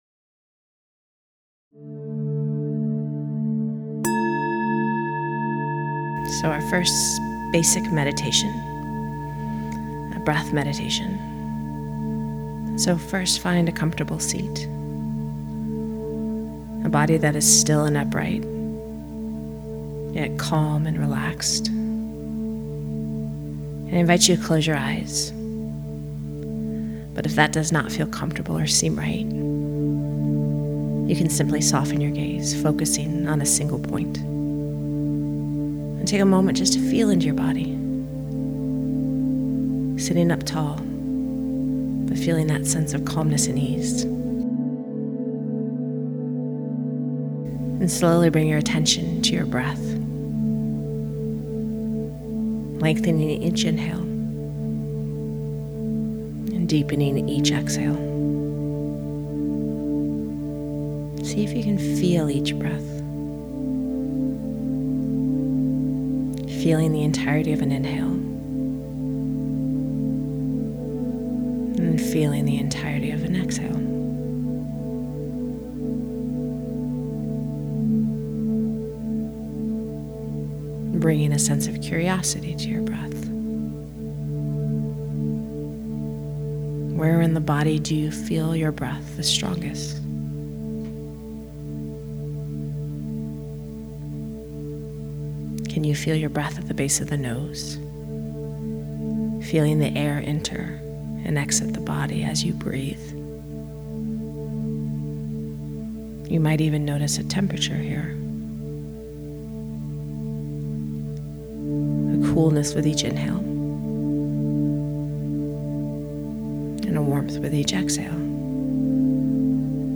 basic-breathe-meditation.mp3